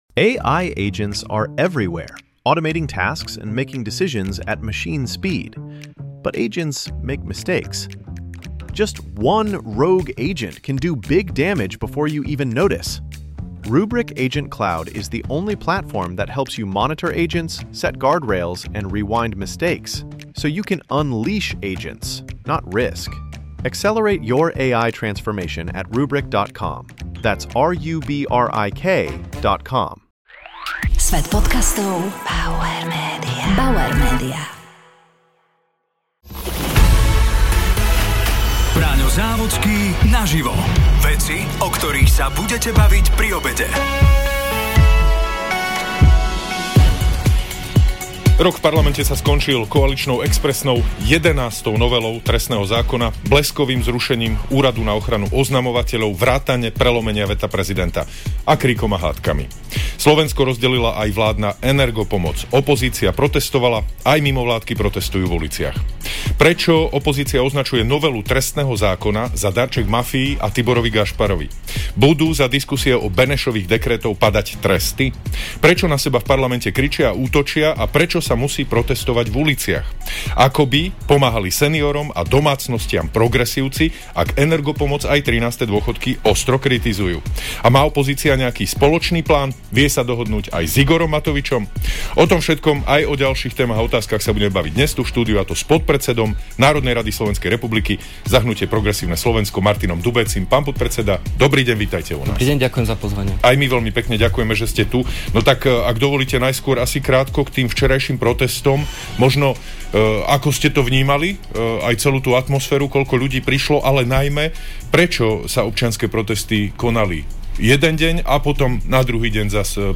Braňo Závodský sa rozprával s podpredsedom Národnej rady SR za hnutie Progresívne Slovensko Martinom Dubécim.